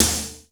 DANCE SD 4.wav